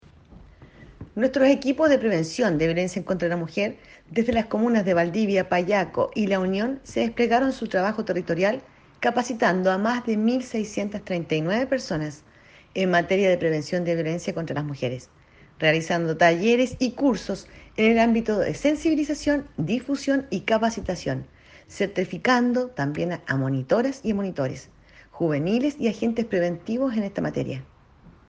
REPORTAJE-CUÑA-04-DIRECTORA-SERNAMEG-Prevención-de-Violencia-contra-la-Mujer.mp3